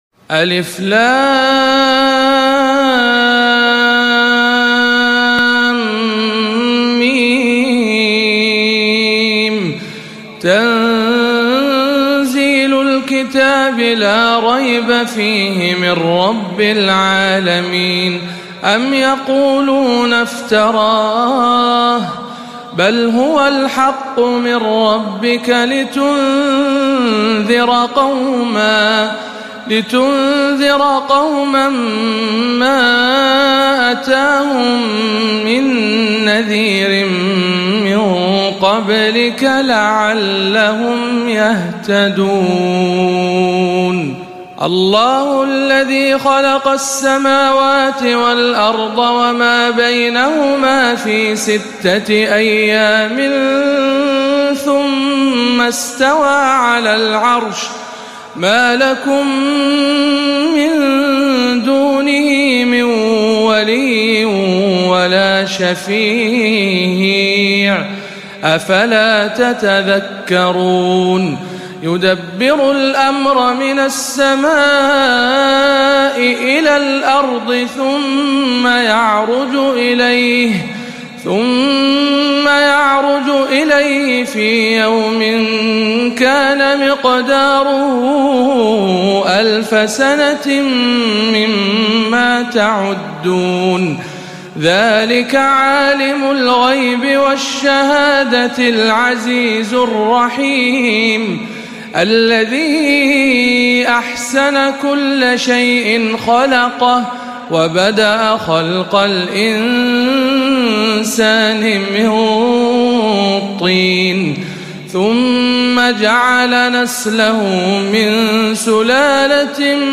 04. سورة السجدة بمسجد الحسين بن علي بخليص